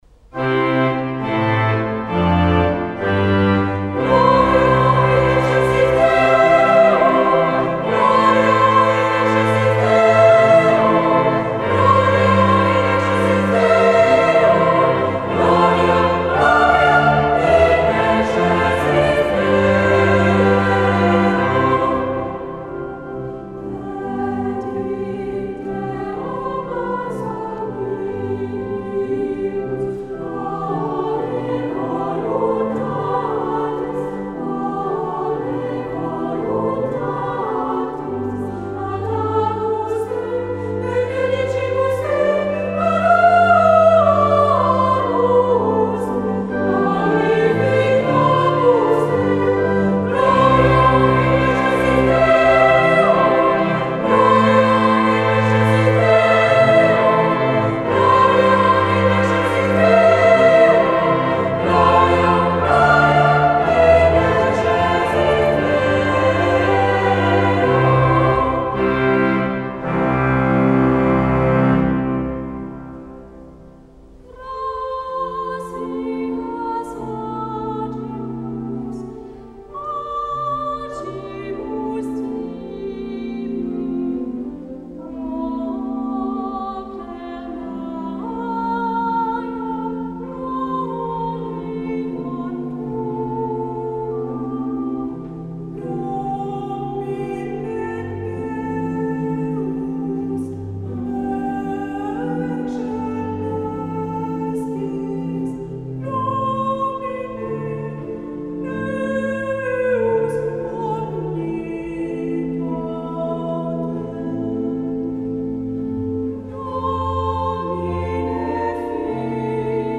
2 et 3 voix égales + orgue
Audios : version d'origine pour voix égales et orgue